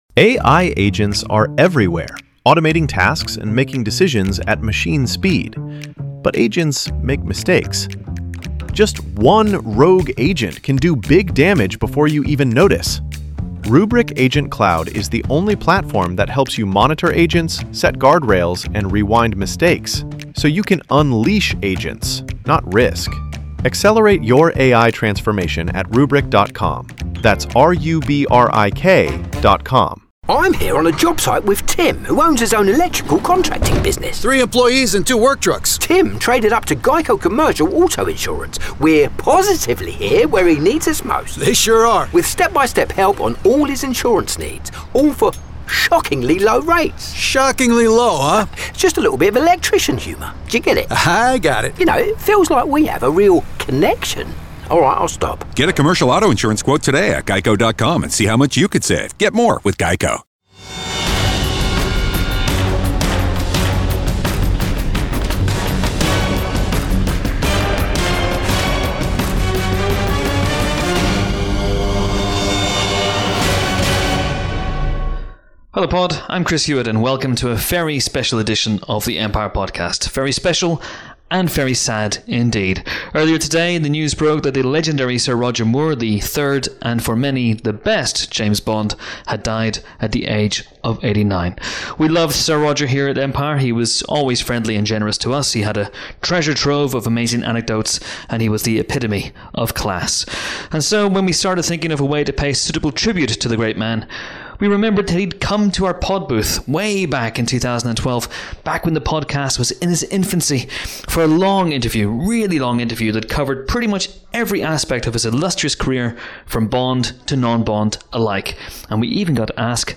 In tribute to the legendary Sir Roger Moore, who sadly passed away today, we are reposting this 40-minute interview special from 2012.